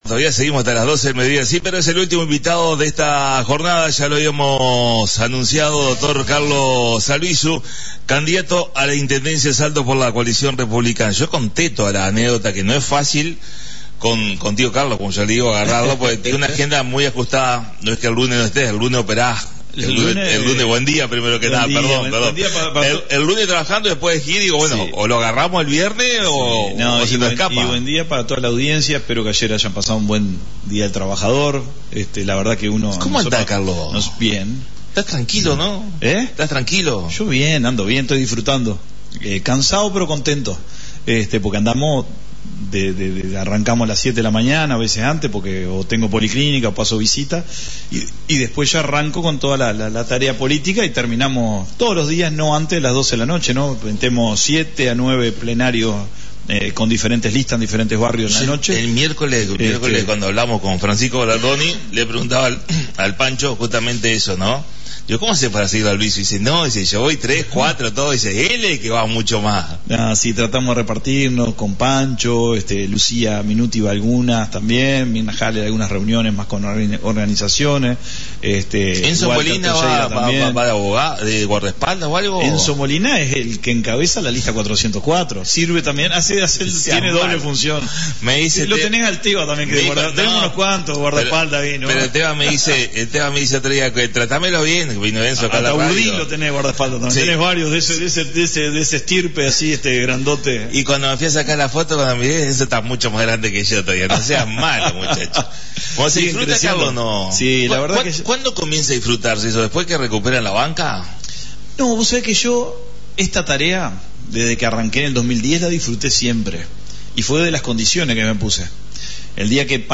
La visita a la Radio del Dr Carlos Albisu Candidato a la Intendencia de Salto por la Coalición Republicana. Ideas, propuestas y el mensaje final rumbo al 11 de mayo